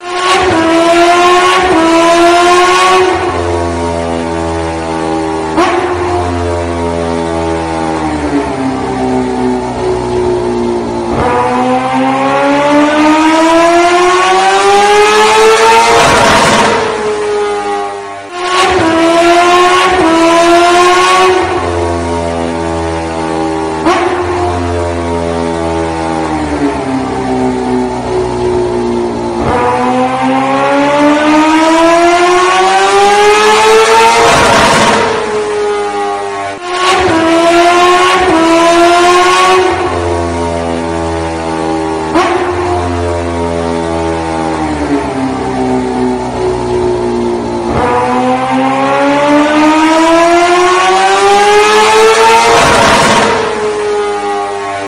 Suara knalpot motor Racing
Kategori: Suara Kendaraan
suara-knalpot-motor-racing-id-www_tiengdong_com.mp3